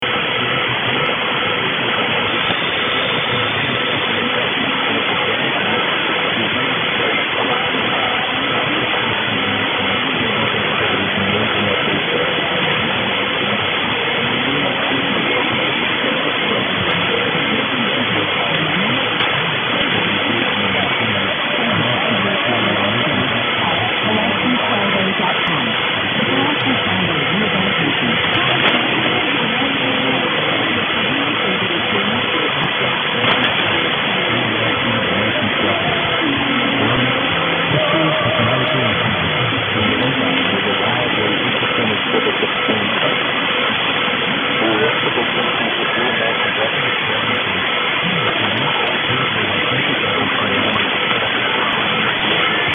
But I do know that after chasing this for a week, I finally heard ids from Springfield Armoury / Armory (American spelling).
Around 0500 is obviously the peak of the propagation at this latitude at this time.